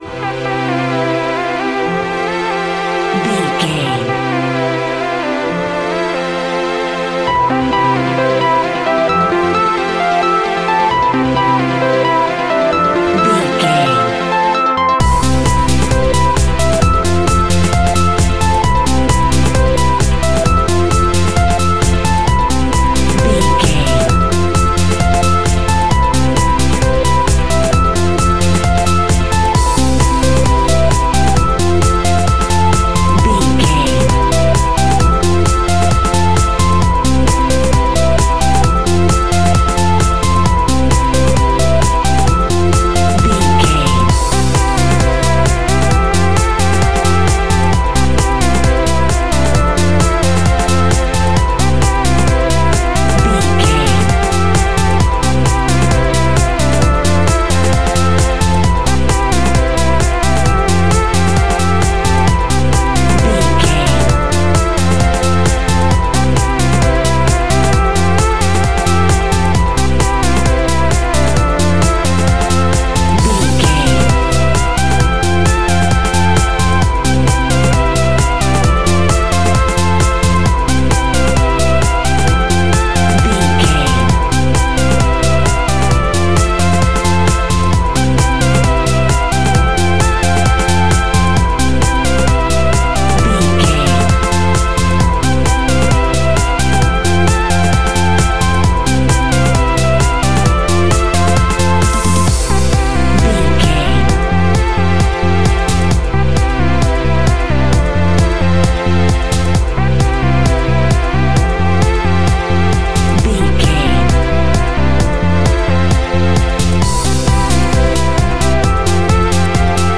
Ionian/Major
pop
pop rock
indie pop
fun
energetic
uplifting
catchy
upbeat
acoustic guitar
electric guitar
drums
piano
organ
bass guitar